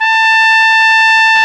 Index of /90_sSampleCDs/Roland LCDP12 Solo Brass/BRS_Piccolo Tpt/BRS_Picc.Tp 1